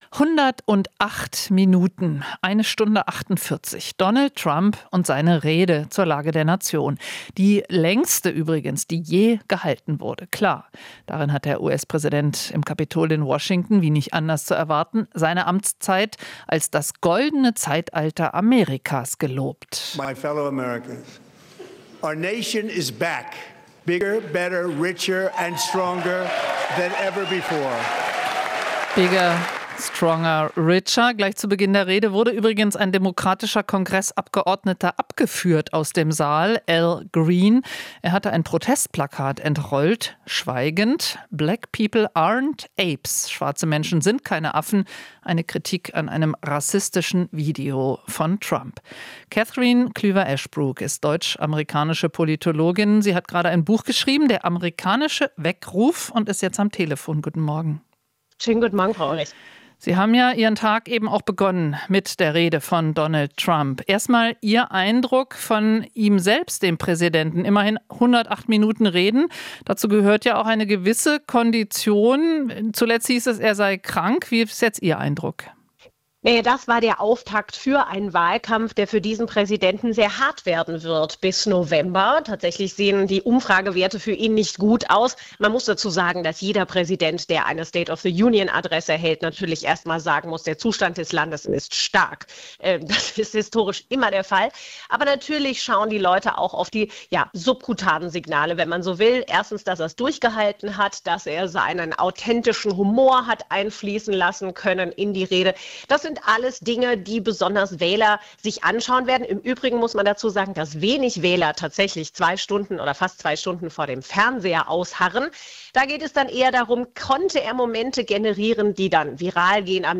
Politologin: Trump-Rede war Auftakt zu hartem Wahlkampf
In Interviews, Beiträgen und Reportagen bilden wir ab, was in der Welt passiert, fragen nach den Hintergründen und suchen nach dem Warum.